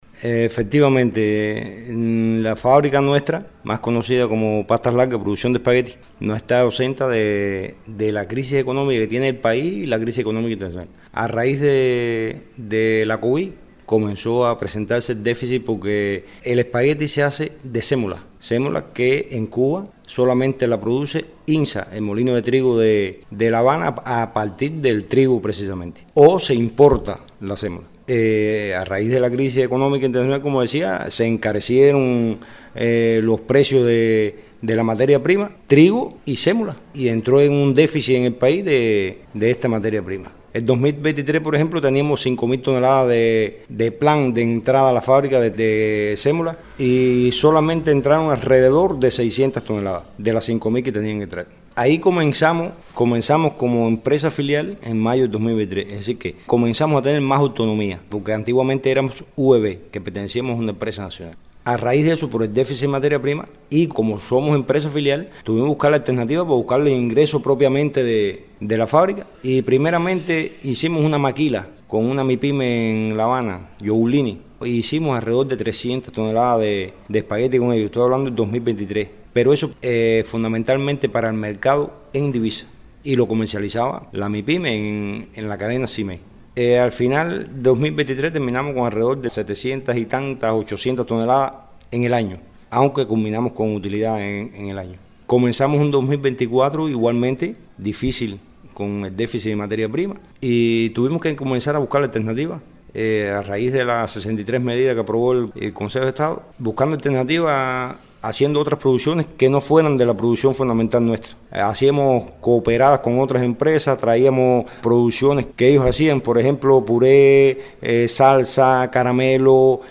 La Empresa Filial de Productos Alimenticios Doña Martha, conocida como Pastas Largas en Cruces, no tuvo un buen primer semestre. Ante la búsqueda de alternativas lograron cerrar el año 2024 con mejores resultados. Escuchemos las declaraciones